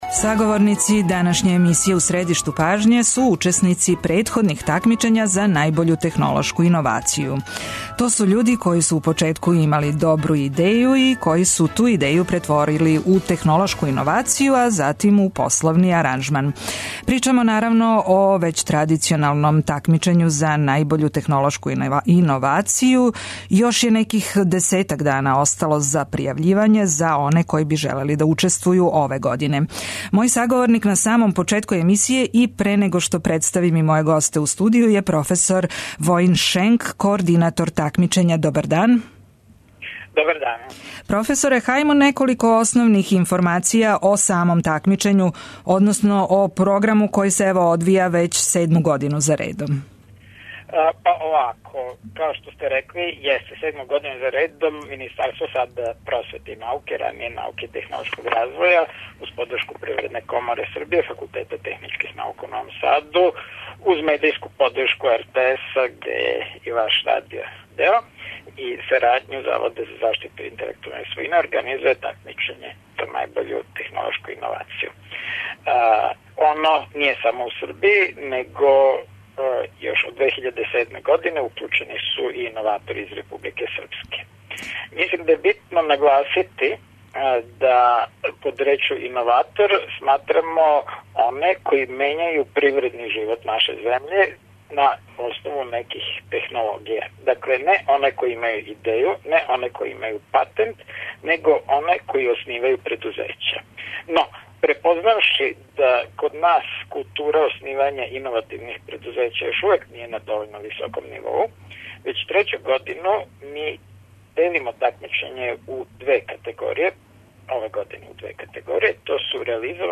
Гости емисије су учесници претходних такмичења који ће испричати своја искуства и посаветовати оне који желе да се укључе ове године. Питаћемо их и како су реализовали своје идеје, да ли су покренули посао и како им се све то исплатило.